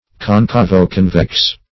Concavo-convex \Con*ca`vo-con"vex\, a.